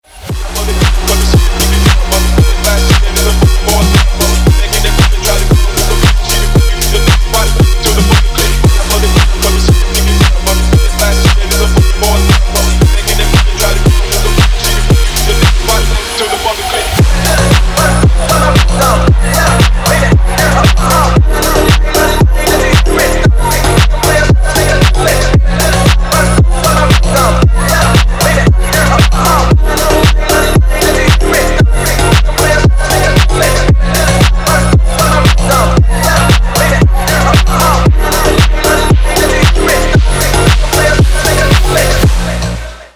Бодрый фонк рингтон
бодрый фонк на звонок